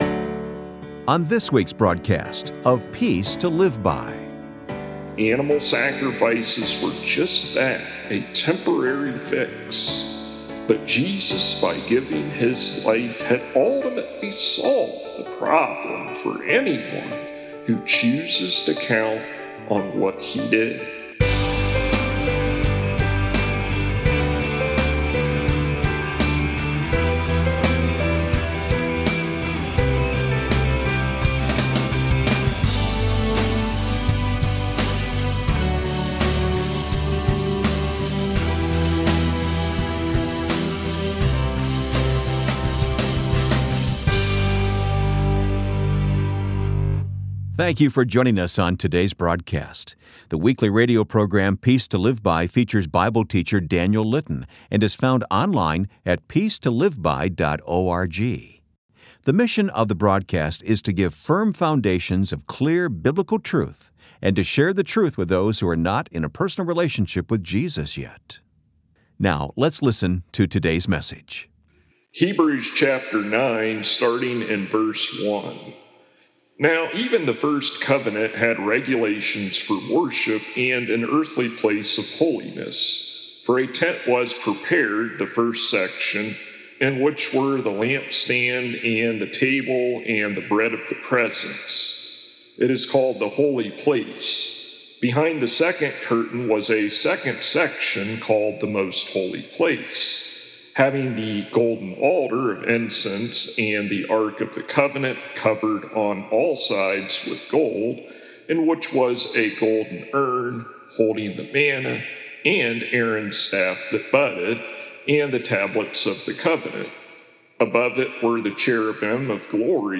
This week's broadcast contains full sermon.